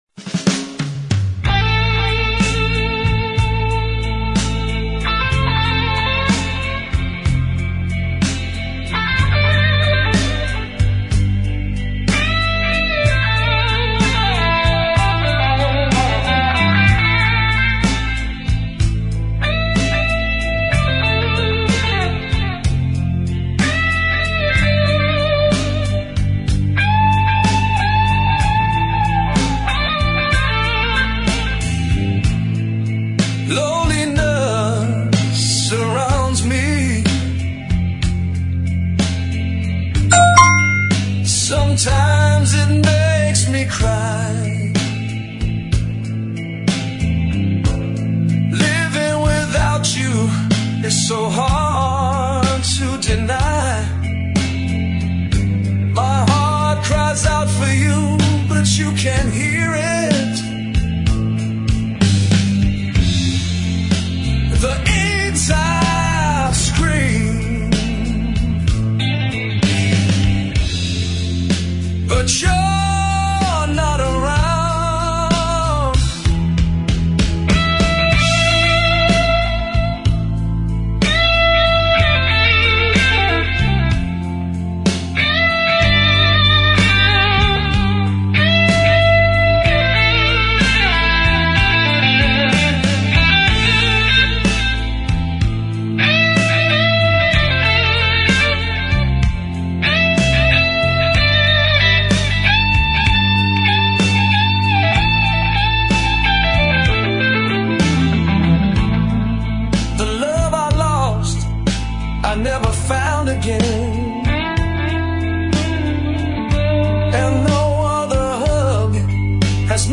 Blues Jazz